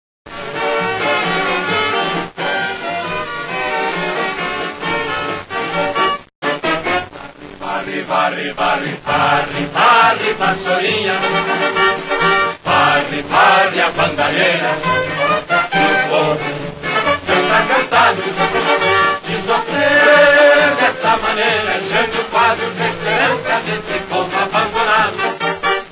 Jingle de J�nio Quadros